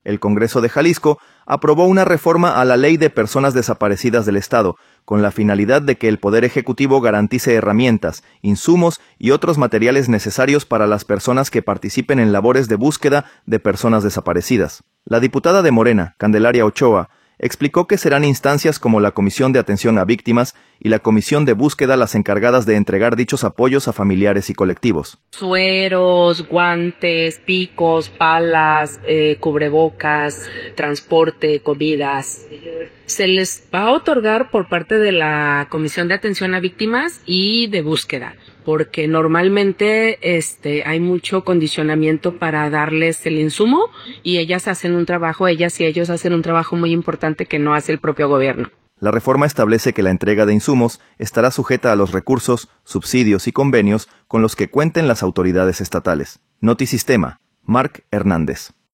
El Congreso de Jalisco aprobó una reforma a la Ley de Personas Desaparecidas del Estado, con la finalidad de que el Poder Ejecutivo garantice herramientas, insumos y otros materiales necesarios para las personas que participen en labores de búsqueda de personas desaparecidas. La diputada de Morena, Candelaria Ochoa, explicó que serán instancias como la Comisión de Atención a Víctimas y la Comisión de Búsqueda las encargadas de entregar dichos apoyos a familiares y colectivos.